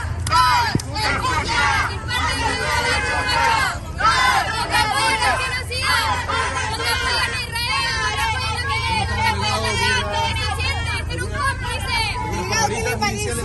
cuna-incidentes-ambiente.mp3